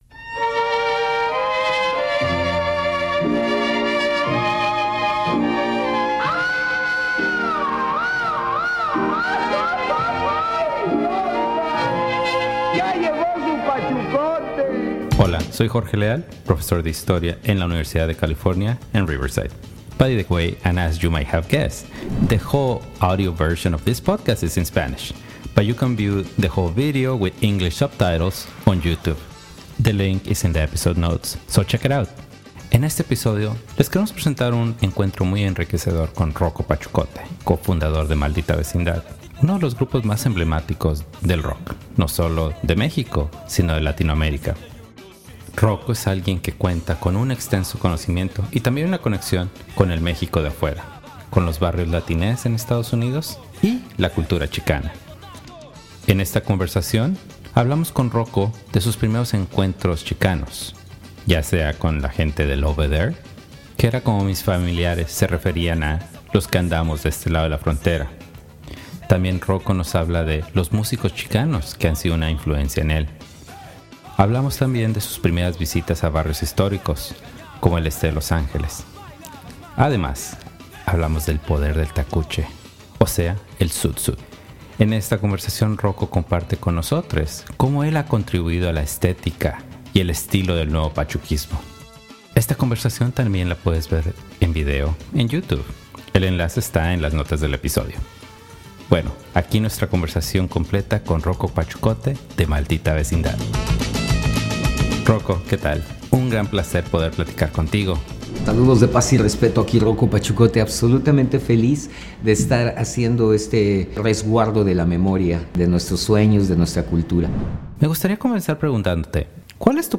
The version here is performed by the students of Mira Monte Music Program in South Los Angeles.